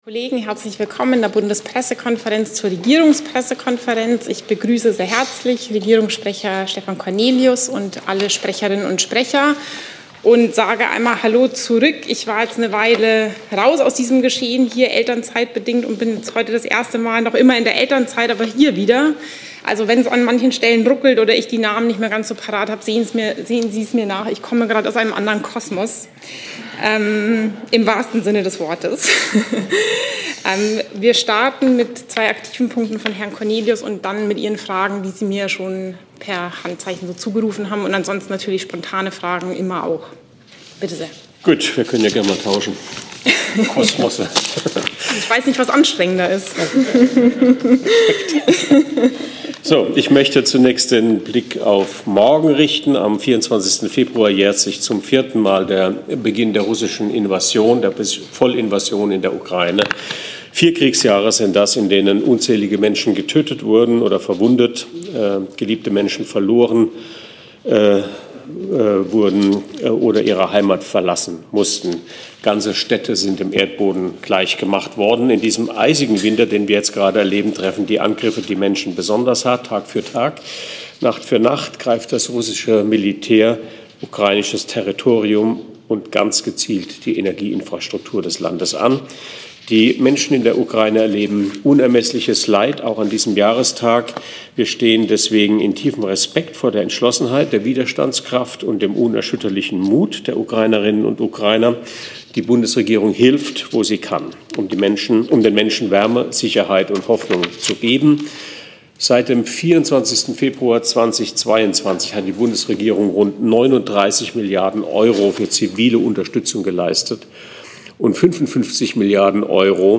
Regierungspressekonferenz in der BPK vom 23. Februar 2026